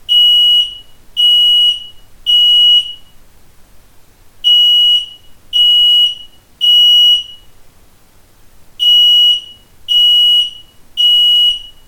• Lautstärke im Test: 89,3 dBA
Alarm / Schallpegel
bosch-ferion-3000-o-rauchmelder-alarm.mp3